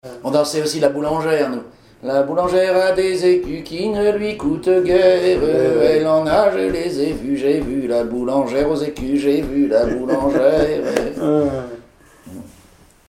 Rondes enfantines à baisers ou mariages
Pièce musicale inédite